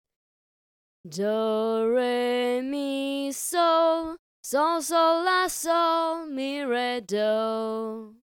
Solfa for Melody 4
Ex-4e-solfa-melody.mp3